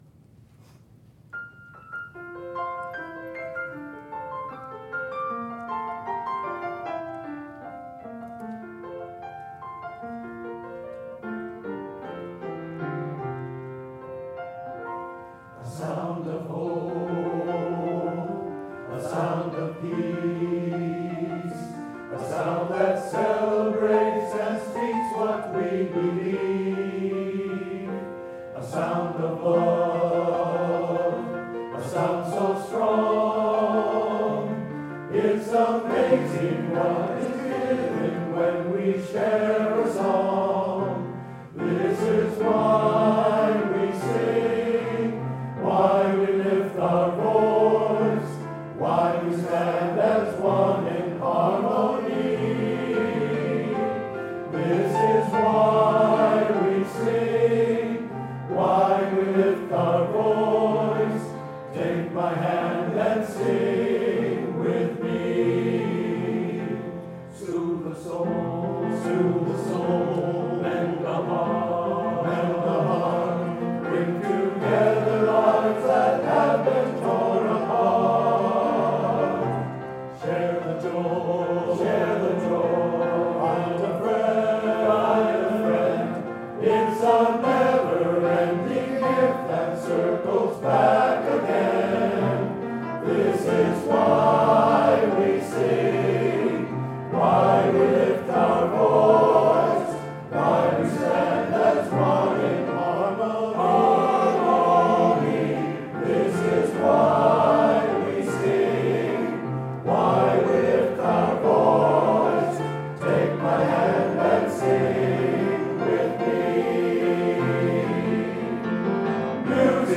The Barnstormers Lehighton Concert - July 8, 2023